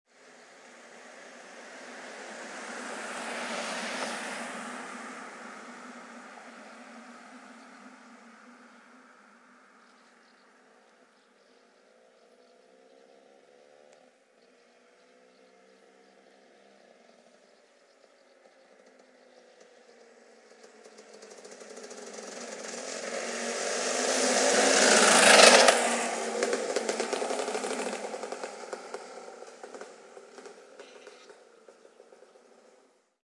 Electric Bike